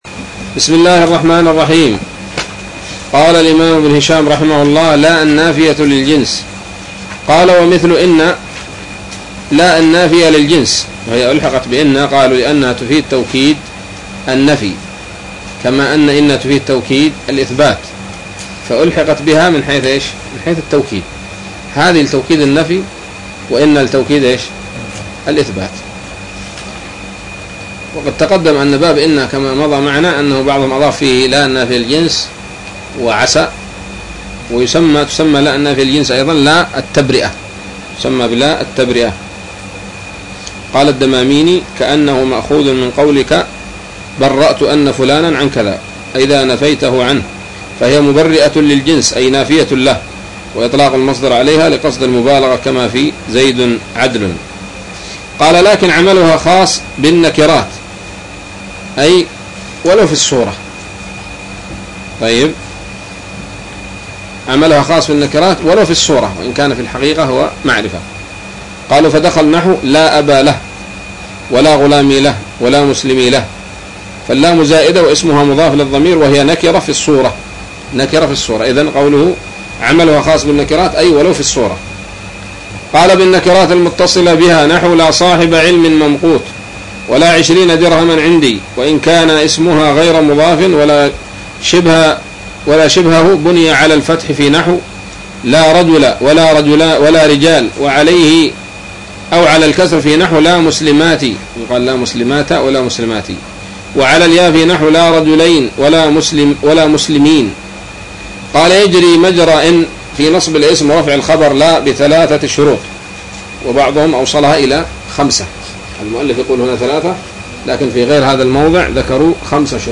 الدرس السابع والستون من شرح قطر الندى وبل الصدى